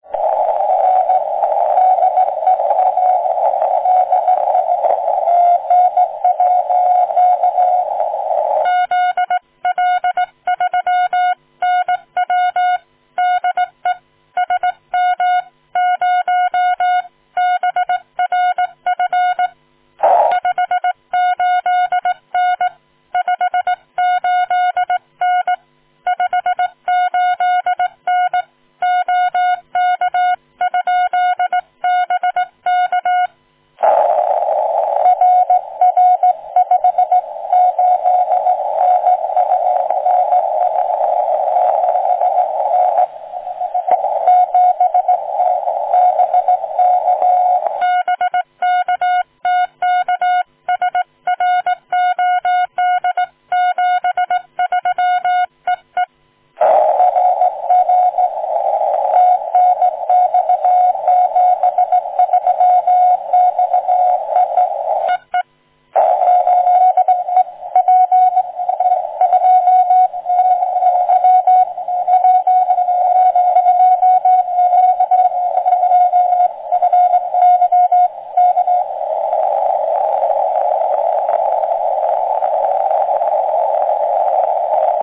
Had an amazing QSO on 20m CW late in the evening.
One could hear when he was passing over better and worse ground. Very distinct QSB.
Attached is a sound clip from the very end of our contact.